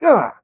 m_pain_1.ogg